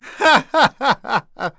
Zafir-Vox_Happy3.wav